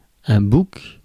Ääntäminen
Synonyymit barbiche bouquin satyre menon Ääntäminen France: IPA: [buk] Haettu sana löytyi näillä lähdekielillä: ranska Käännös Substantiivit 1. kitsehabe Muut/tuntemattomat 2. kits Suku: m .